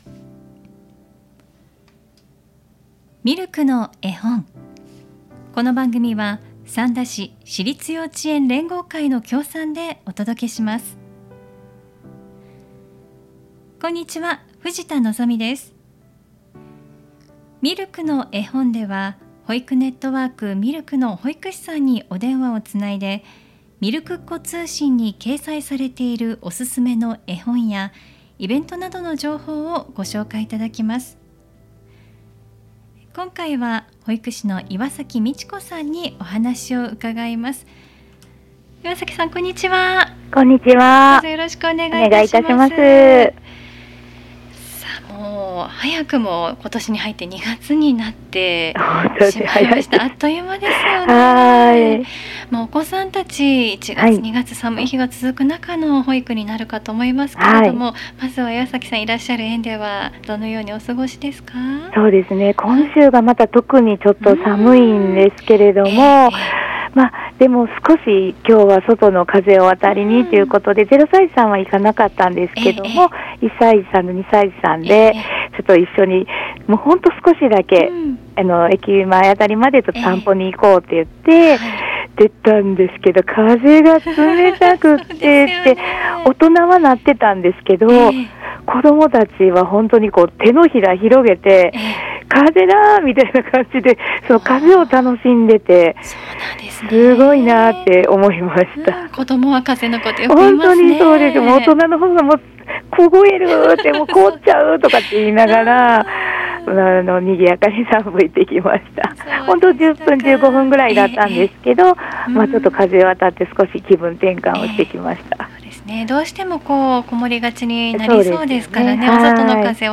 保育ネットワーク・ミルクの保育士さんにお電話をつないで、みるくっ子通信に掲載されているおすすめの絵本やイベント・施設情報などお聞きします📖